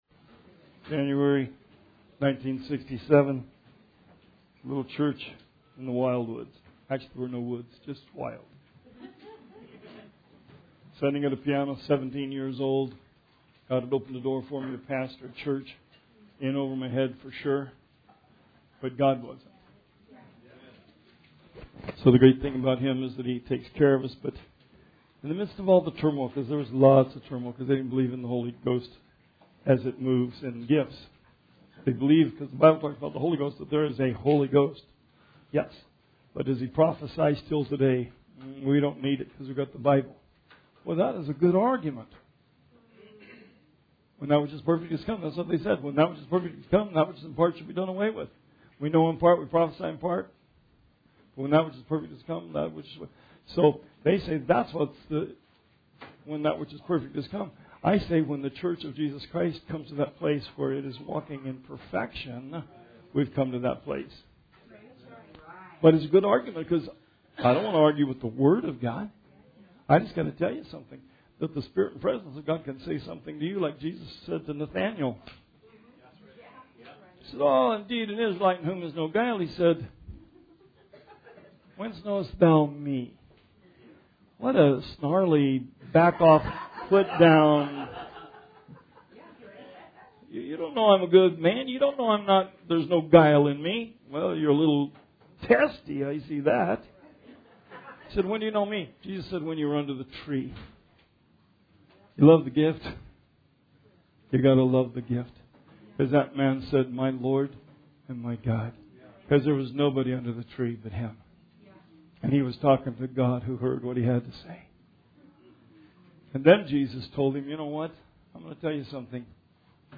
Bible Study 7/17/19